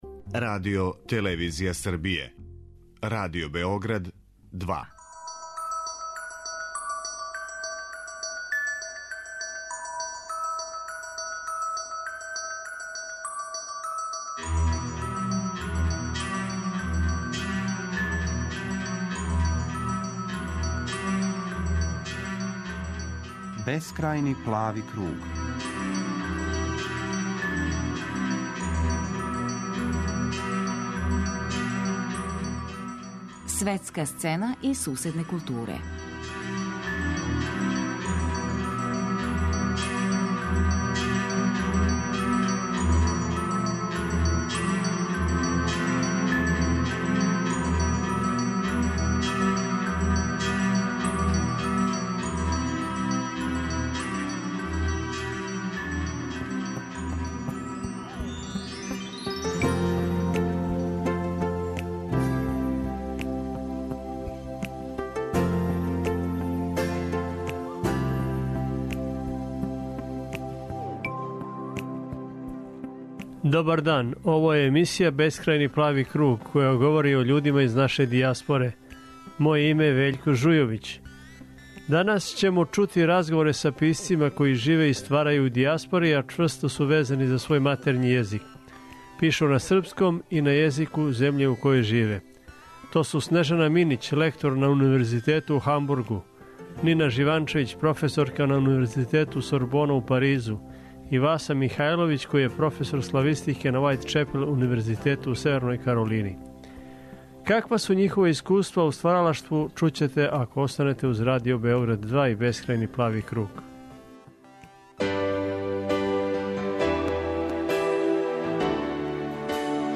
Данас ћемо чути разговоре са писцима који живе и стварају у дијаспори, а чврсто су везани за свој матерњи језик. Пишу на српском и на језику земље у којој живе.